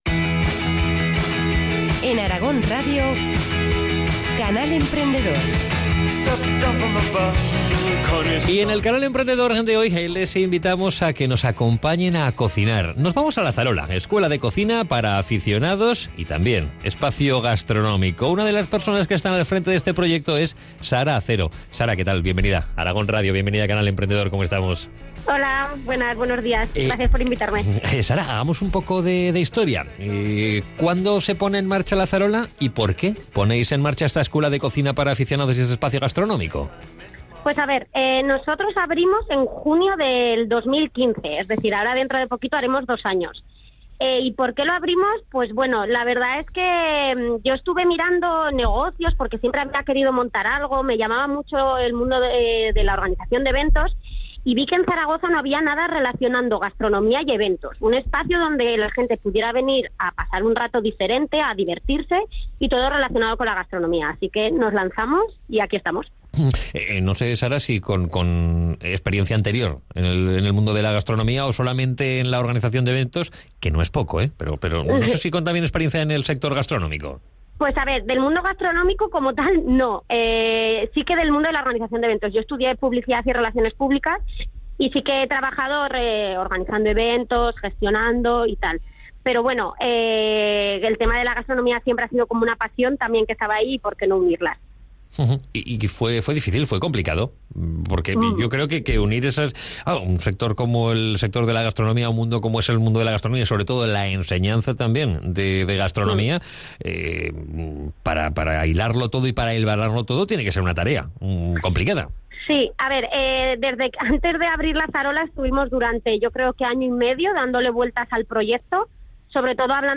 Durante una breve entrevista hemos compartido lo bueno y lo malo de emprender y hemos adelantado pequeños proyectos que todavía están por venir.